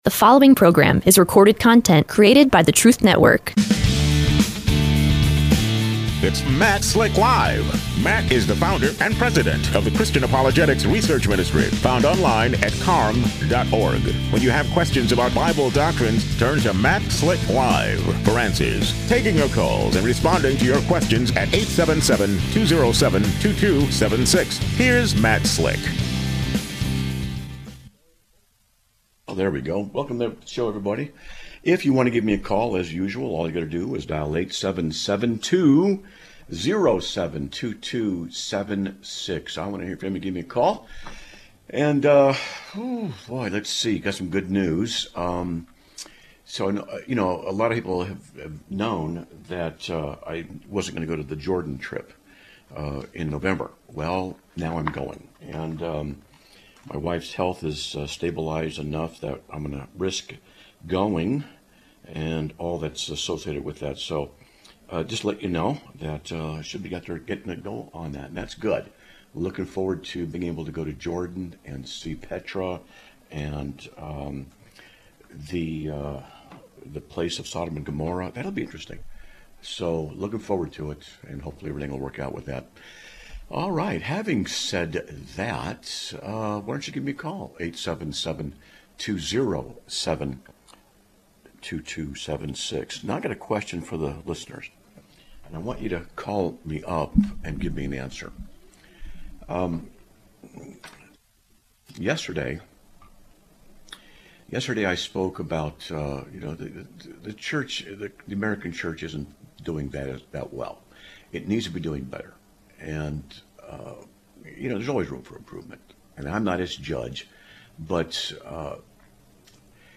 Live Broadcast of 09/08/2025